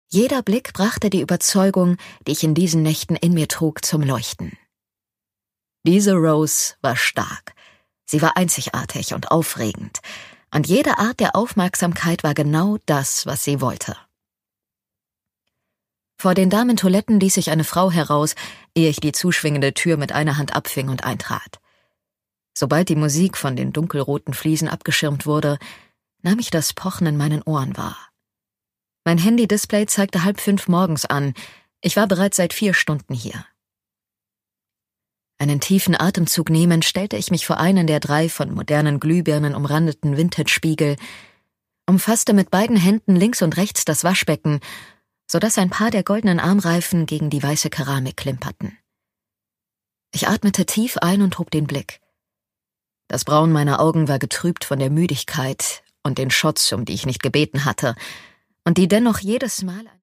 Sara West: Blooming Trust - Rose Garden, Band 2 (Ungekürzte Lesung)
Produkttyp: Hörbuch-Download